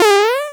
boingo.wav